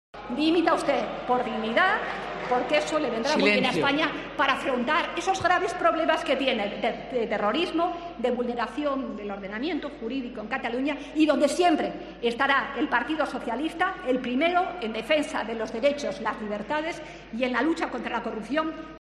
Pleno del Congreso